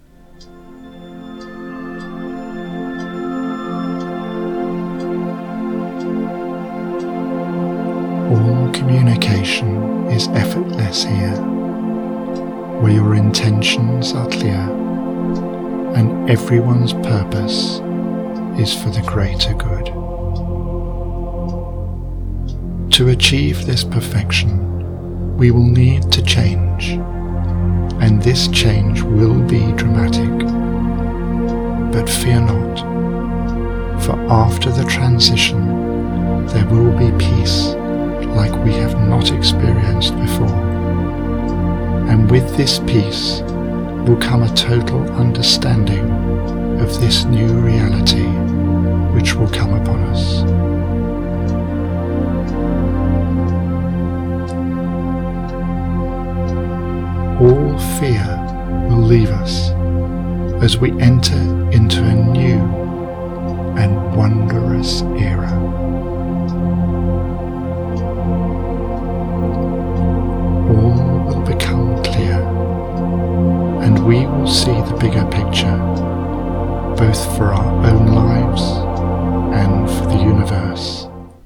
Joy in your Heart is my second guided meditation CD, released in June 2016 and the follow up to Journey to Inner Peace.